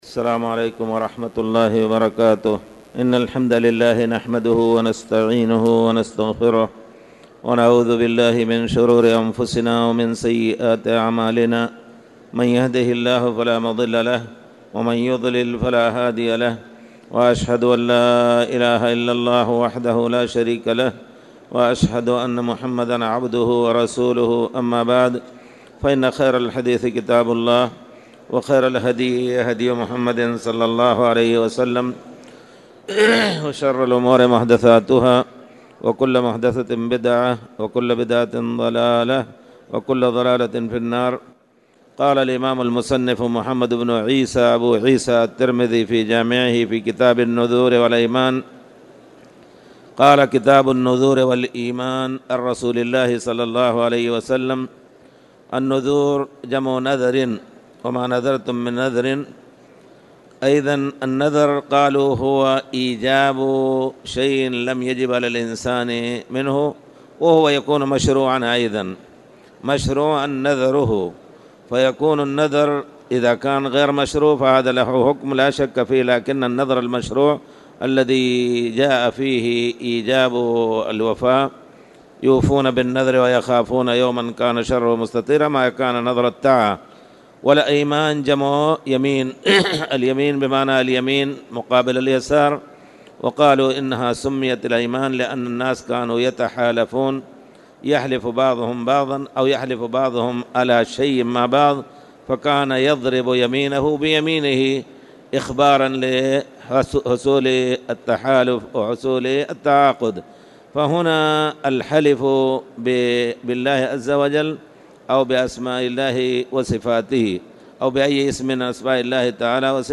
تاريخ النشر ٢٦ جمادى الآخرة ١٤٣٨ هـ المكان: المسجد الحرام الشيخ